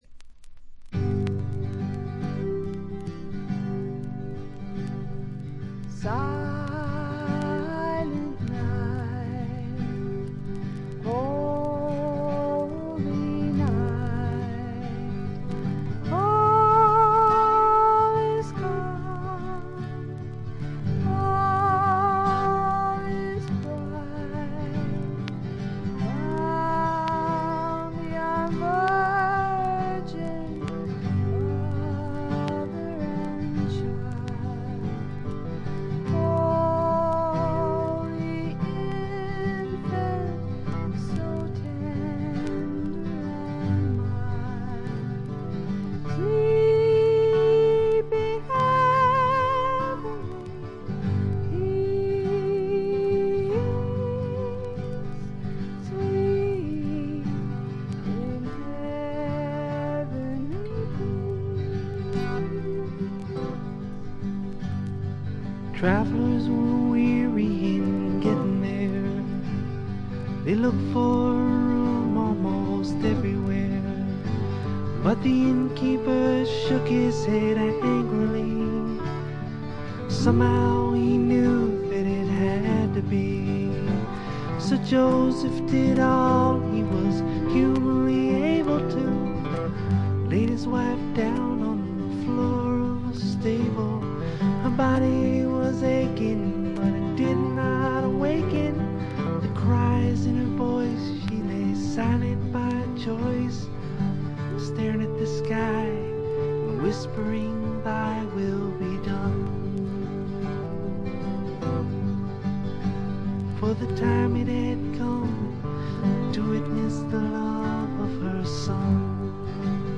バックグラウンドノイズ、チリプチやや多めですが鑑賞を妨げるようなものはありません。
試聴曲は現品からの取り込み音源です。